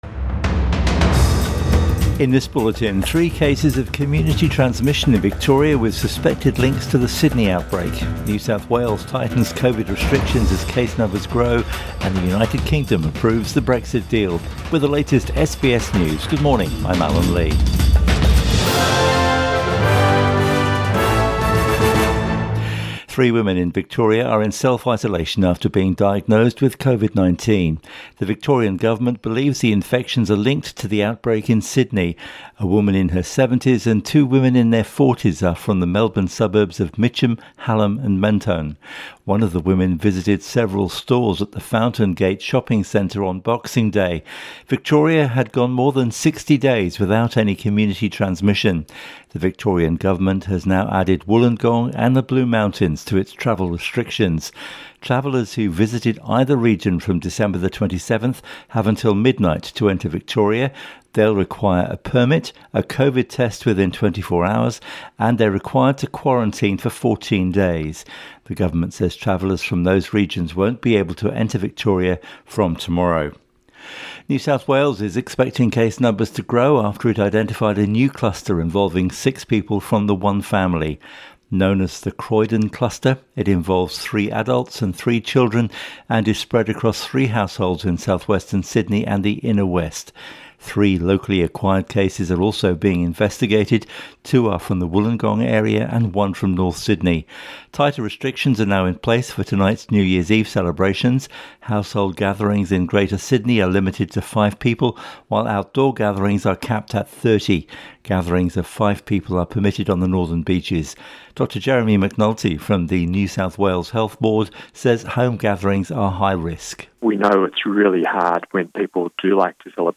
AM Bulletin 31 December 2020